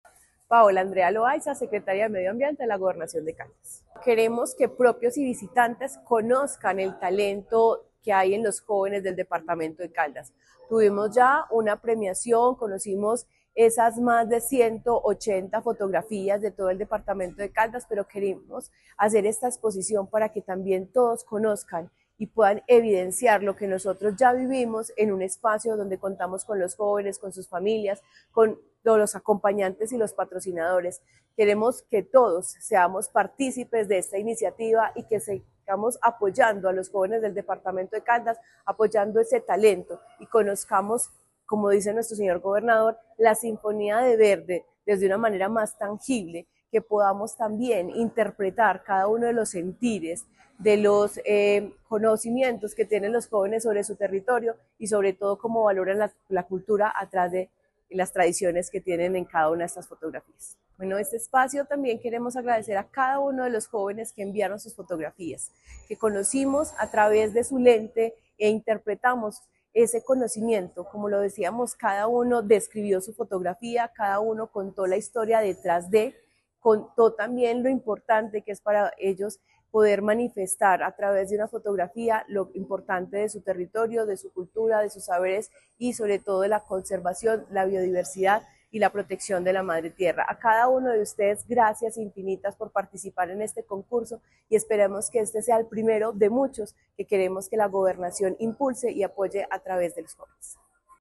Paola Andrea Loaiza Cruz, secretaria de Medio Ambiente de Caldas
Paola-Loaiza-secretaria-.mp3